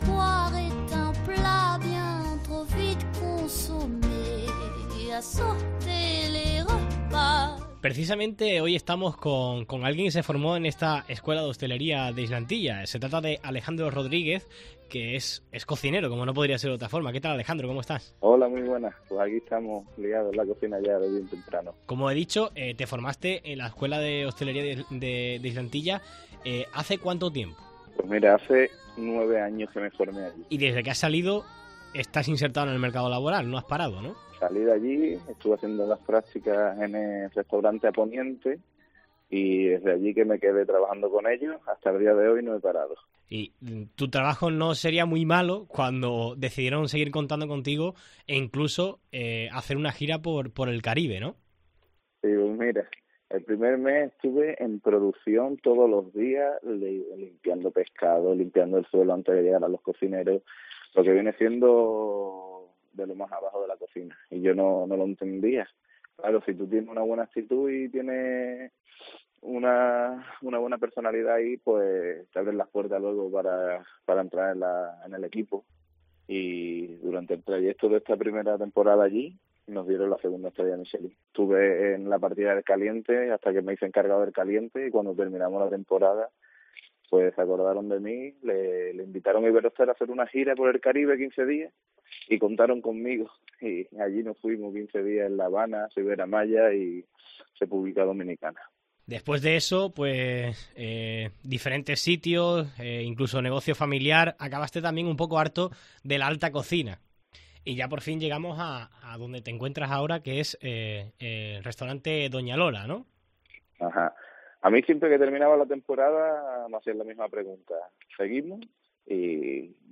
La Escuela de Hostelería de Islantilla pone en marcha su nuevo curso, un centro puntero del que salen grandes profesionales. En Herrera en COPE hablamos con uno de sus ex alumnos